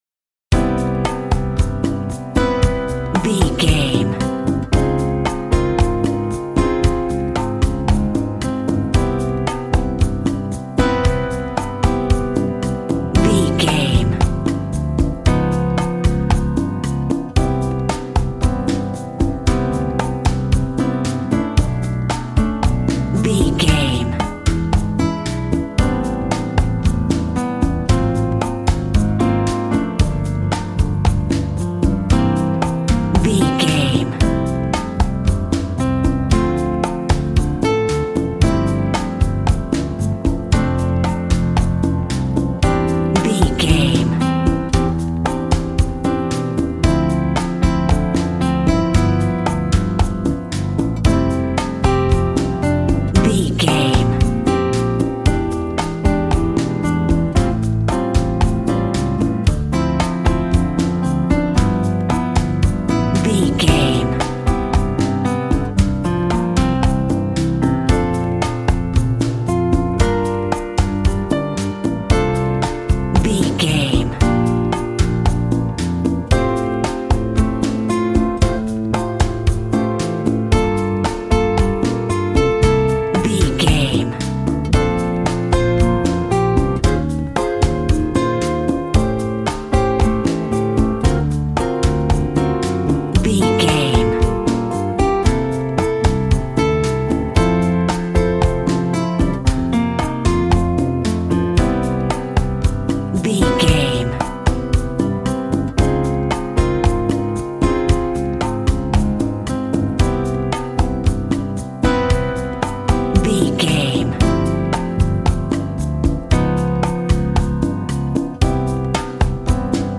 Ionian/Major
funky
energetic
romantic
percussion
electric guitar
acoustic guitar